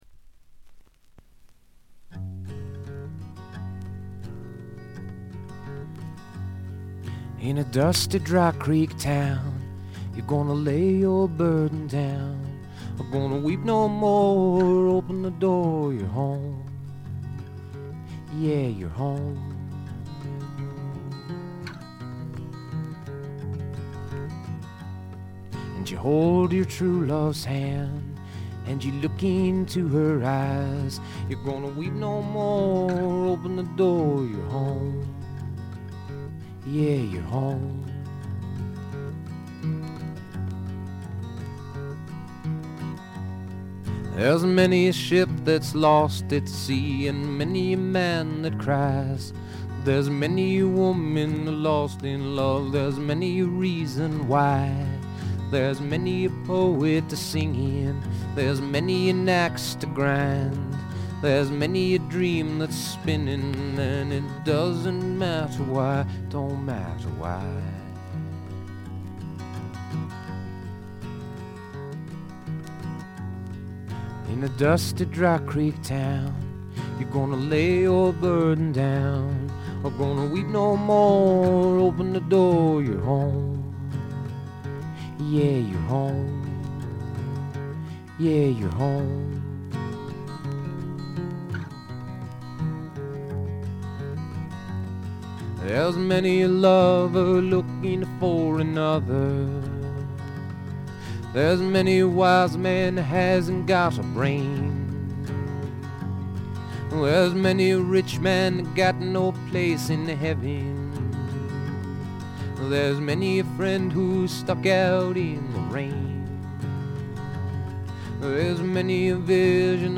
プレスのせいかチリプチ少々出ます。
本人のギター弾き語りを基本に友人たちによるごくシンプルなバックが付くだけのフォーキーな作品です。
とてもおだやかでドリーミーな感覚もがただようフォーク作品です。
試聴曲は現品からの取り込み音源です。
ちなみに試聴曲はA7以外は女性ヴォーカルとのデュエットです。
guitar